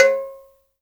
AFRO AGOGO 3.wav